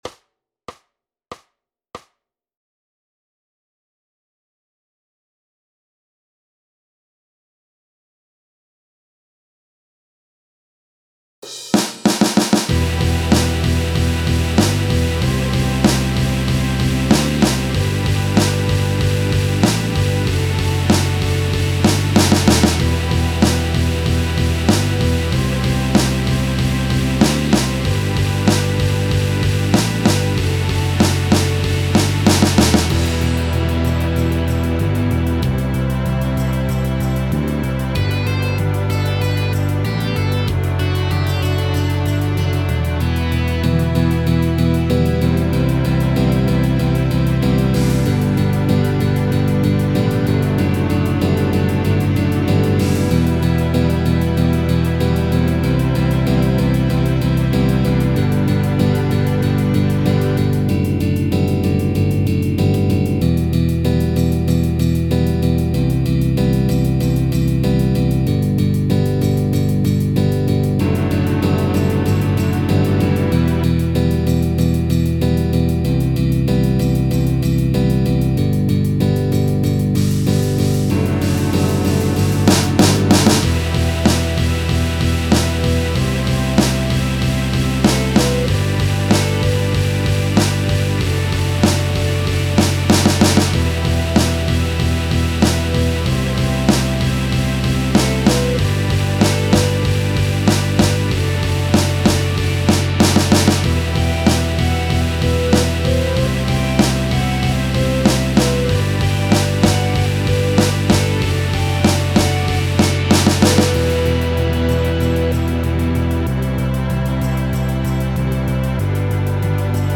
Backing track]